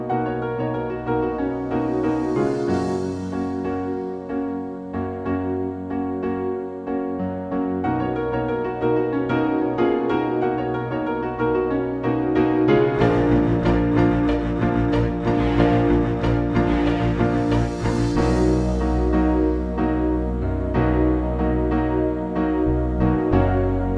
no Backing Vocals R'n'B / Hip Hop 3:35 Buy £1.50